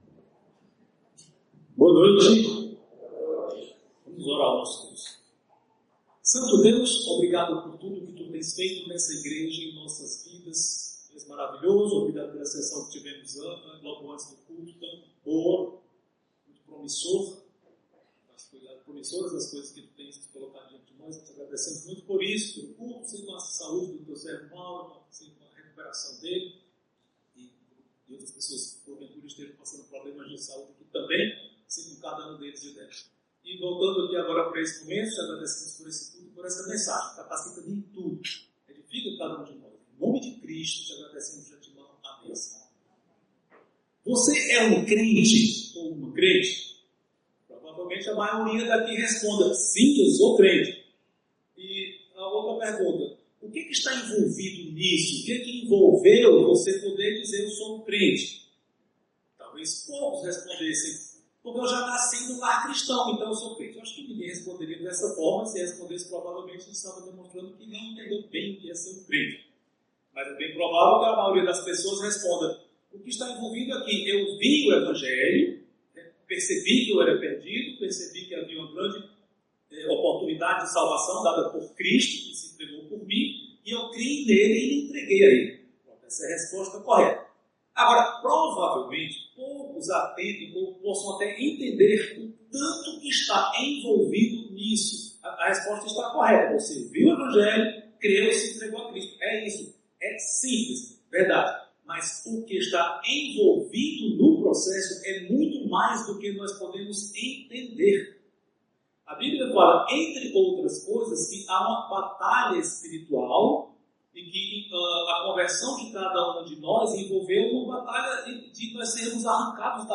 Igreja Batista Luz do Mundo, Fortaleza/CE.
pregação